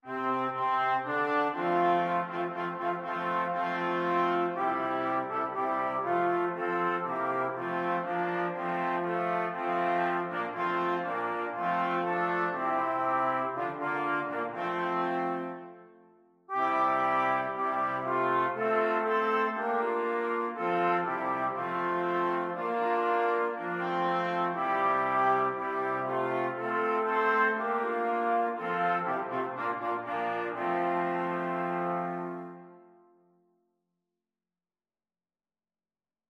(3tp, hn)
Trumpet 1Trumpet 2Trumpet 3French Horn
2/4 (View more 2/4 Music)
Allegro = c.120 (View more music marked Allegro)